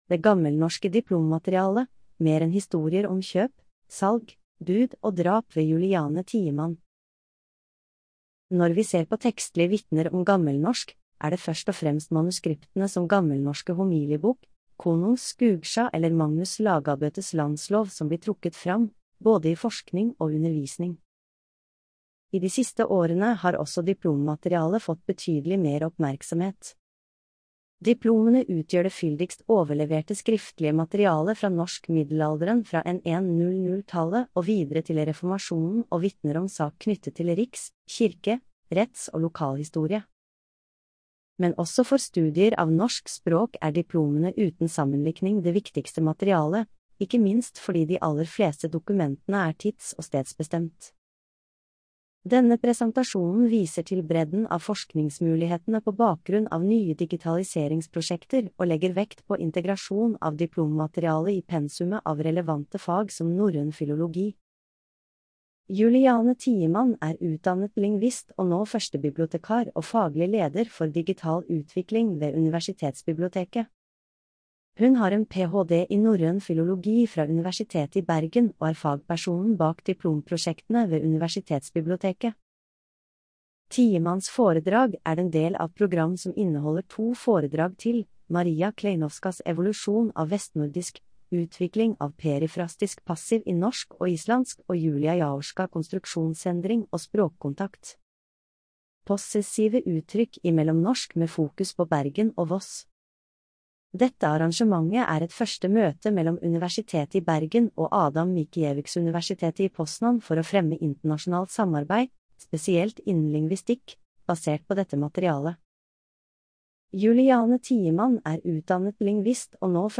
Populærvitenskapelige foredrag. Bryggens Museum.